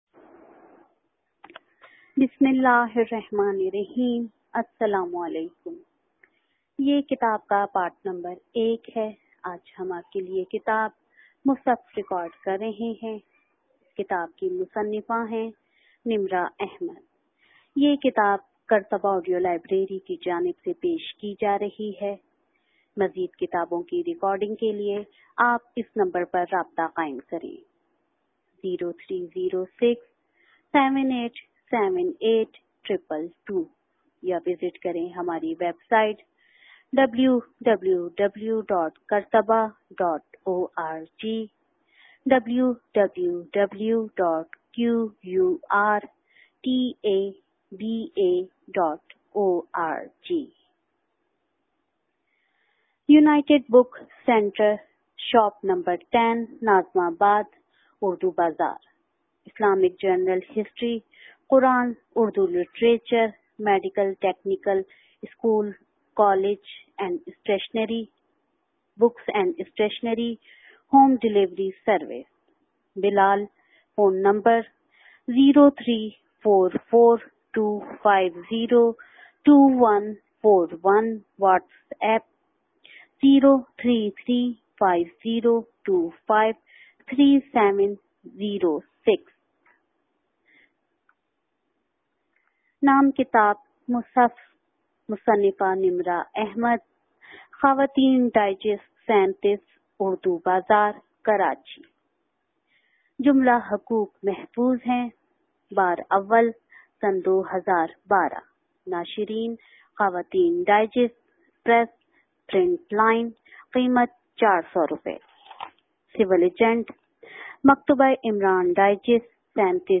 This audio book Musahaf is written by a famous author nimra ahmad and it is listed under novel category.